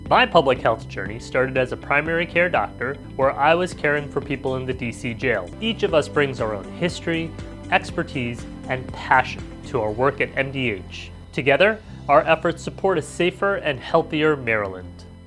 Deputy Health Secretary Dr. Nilesh Kalyanaraman said this year’s theme is “It Starts Here.”  He spoke about his beginnings in the healthcare workforce…